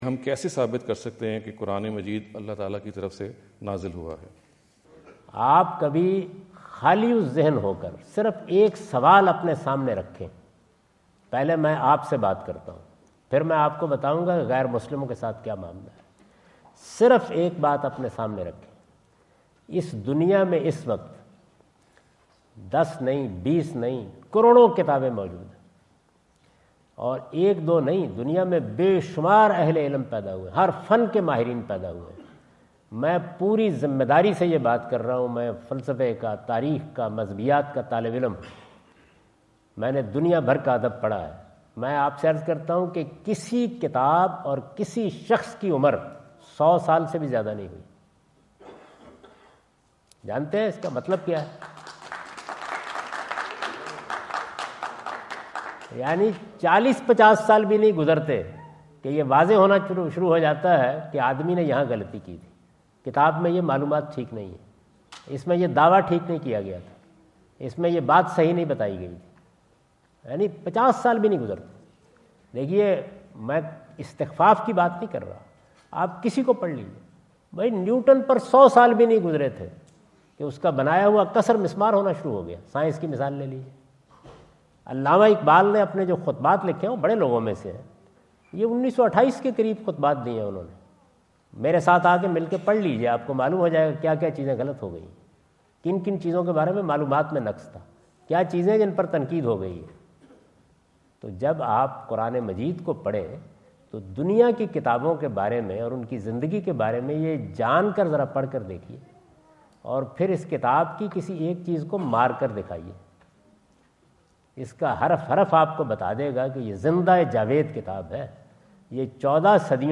Javed Ahmad Ghamidi answer the question about "ٰHow to Prove Quran as a Divine Book?" asked at The University of Houston, Houston Texas on November 05,2017.